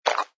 sfx_slurp_bottle03.wav